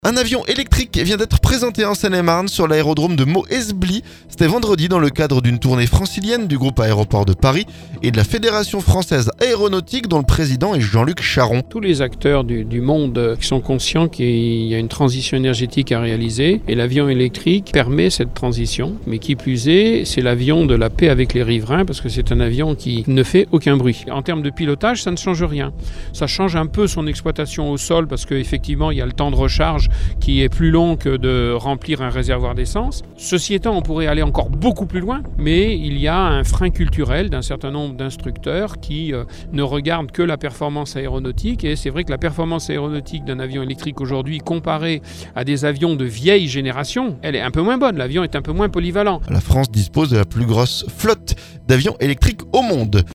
MEAUX-ESBLY - Un avion électrique présenté, notre reportage